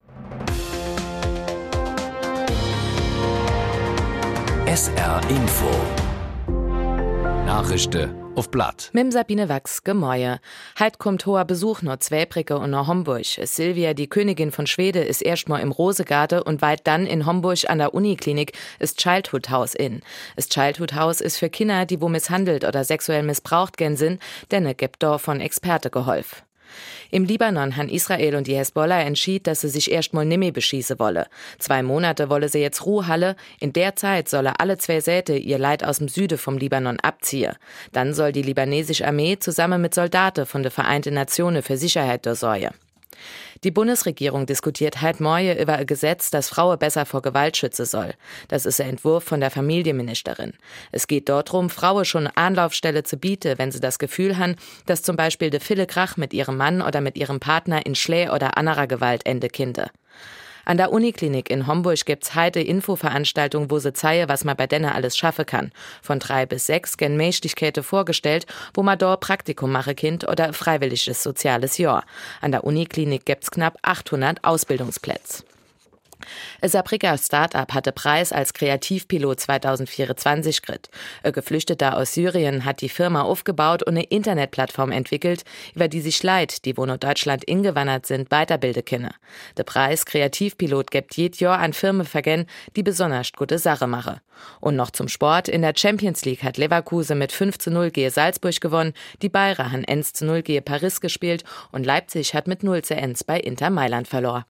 Von Montag bis Freitag gibt es bei SR 3 Saarlandwelle täglich um 08:30 Uhr Schlagzeilen in Mundart. Die "Nachrischde uff platt" werden mal in moselfränkischer, mal in rheinfränkischer Mundart präsentiert. Von Rappweiler bis Dudweiler, von Dillingen bis Püttlingen setzt sich das Team aus Sprechern ganz verschiedener Mundartfärbungen zusammen